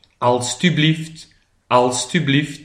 PRONONCIATION :
alsublieft.mp3